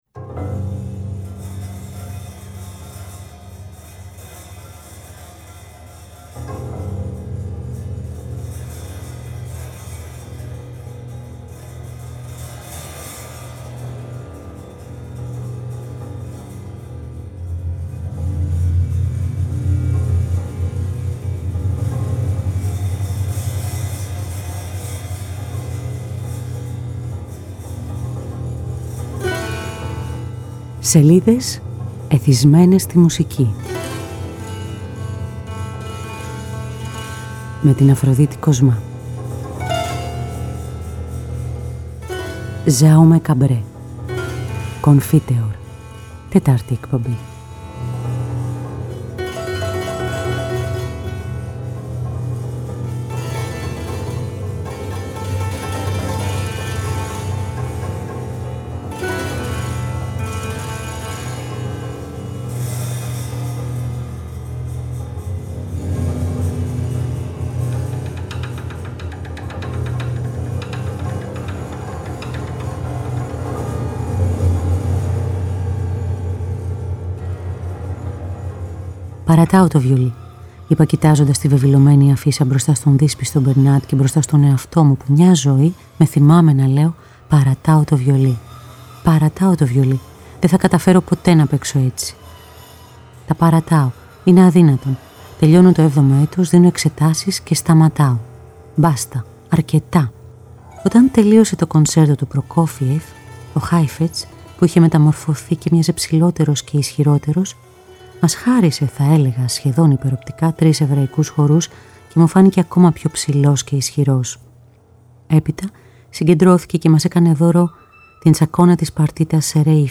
Ακούμε -Sergei Prokofiev / Mariinsky Theatre Symphony Orchestra -Ludwig van Beethoven / London Symphony Orchestra